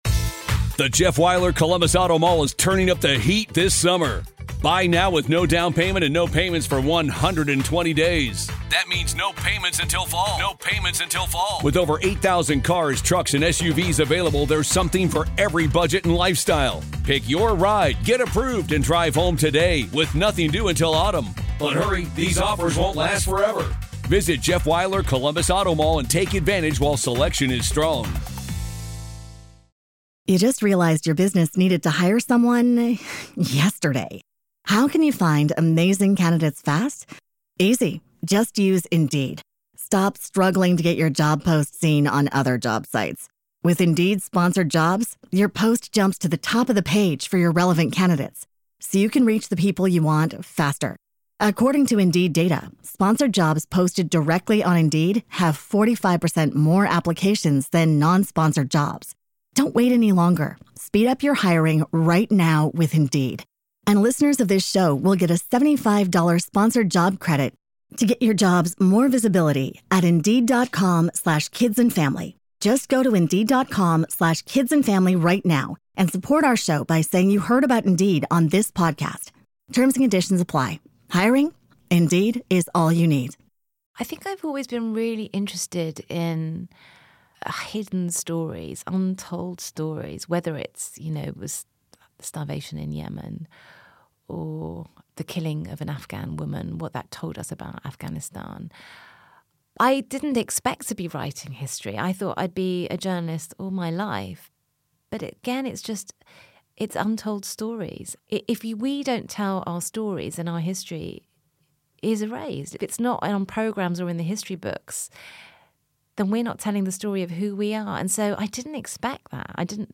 Every week, Vick will be joined by another inspirational woman to discuss the work of incredible female authors.